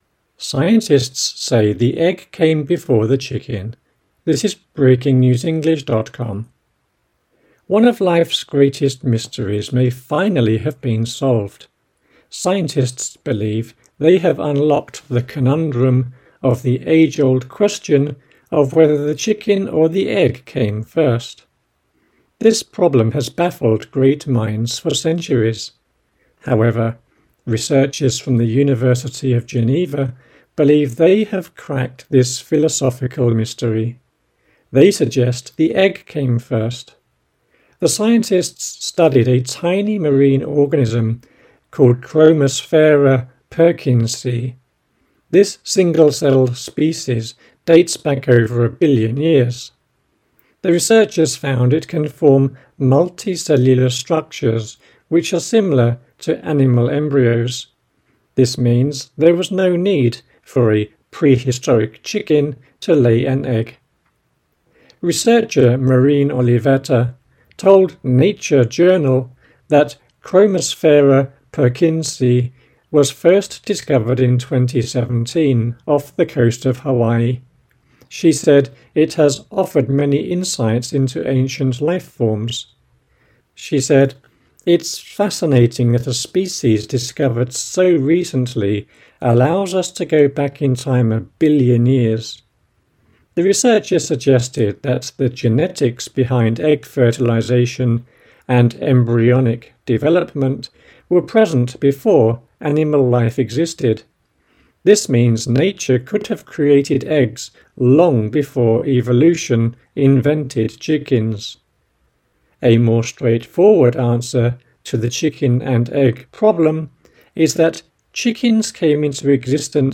AUDIO(Slow)